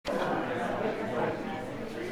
The sermon is from our live stream on 2/22/2026